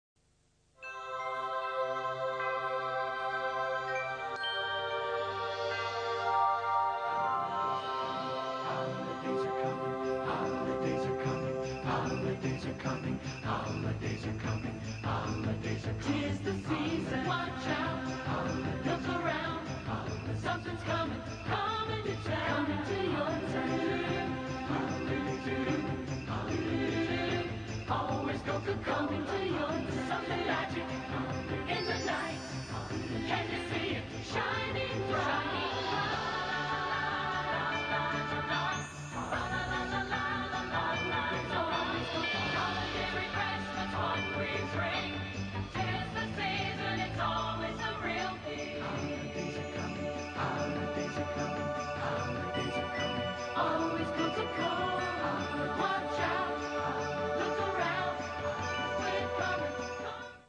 Саундтрек из рекламы